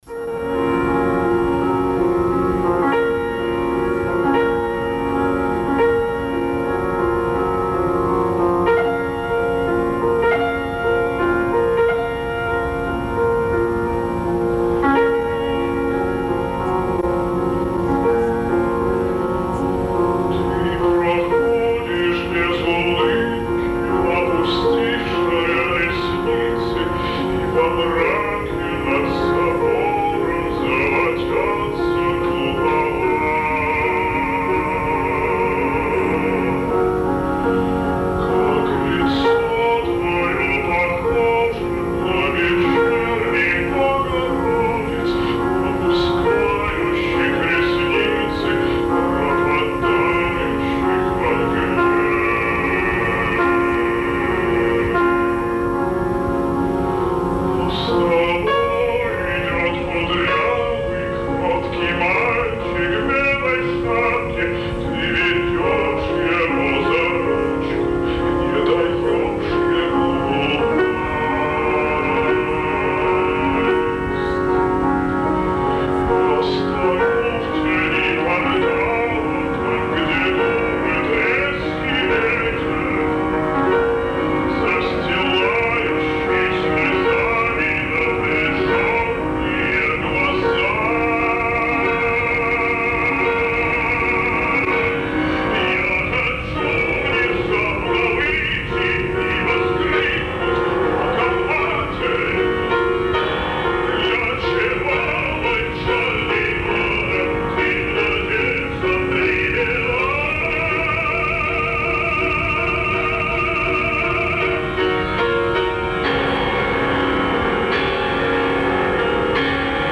Запись с репетиции.
ф-но